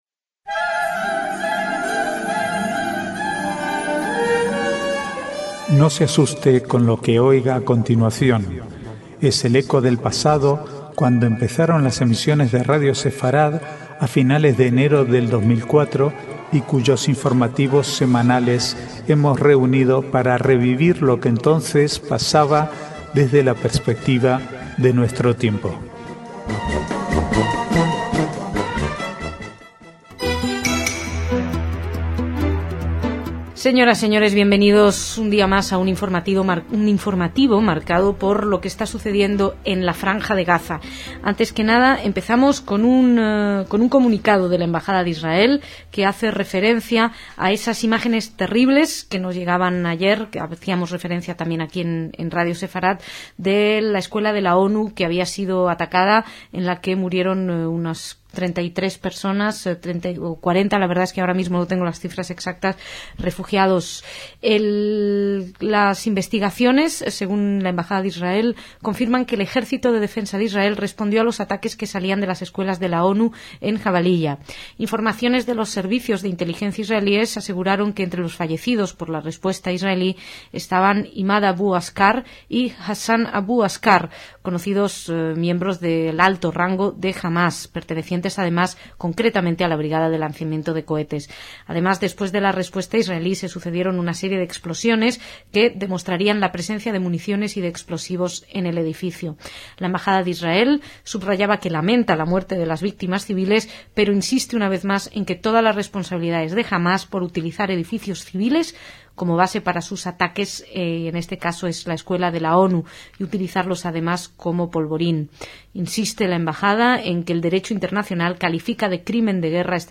Archivo de noticias del 8 al 13/1/2009